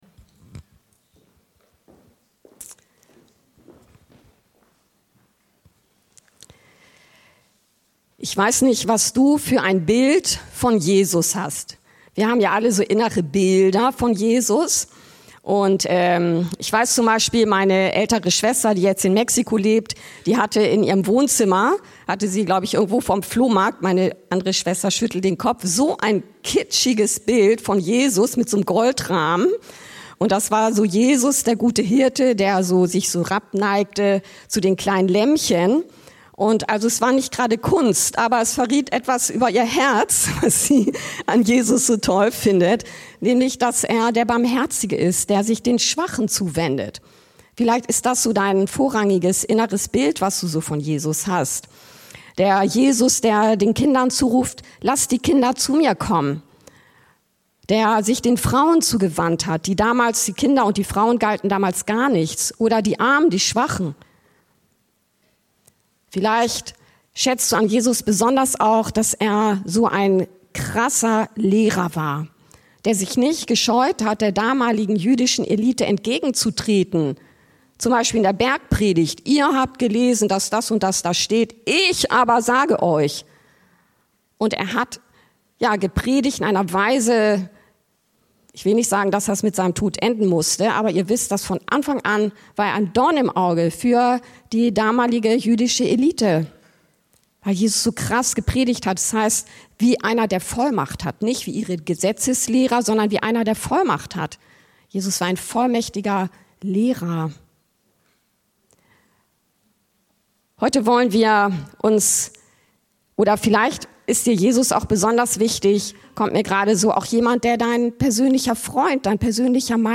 Wie wachse ich in der Heilungsvollmacht? ~ Anskar-Kirche Hamburg- Predigten Podcast